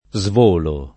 svolo [ @ v 1 lo ]